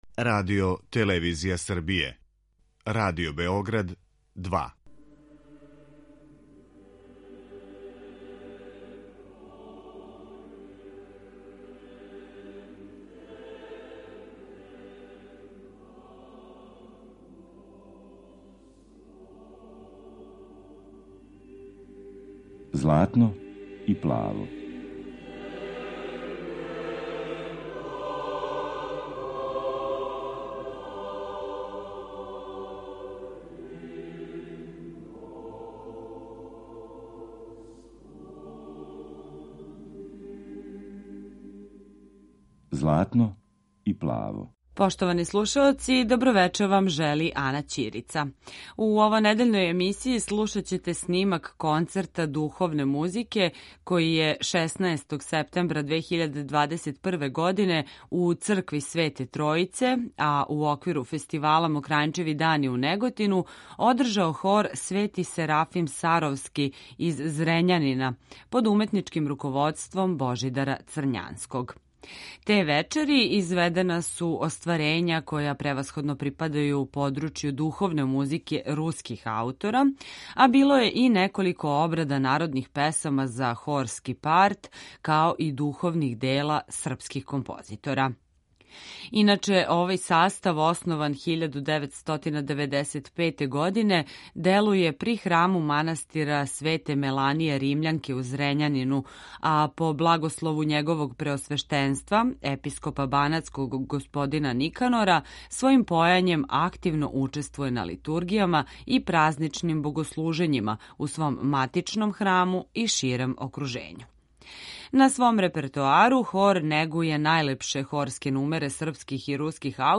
Концерт на Мокрањчевим данима 2021.
Емисија посвећена православној духовној музици.
Емитујемо снимак дела концерта који је 16.9.2021. одрзан у цркви Св.Тројице у Неготину, у оквиру фестивала Мокрањчеви дани.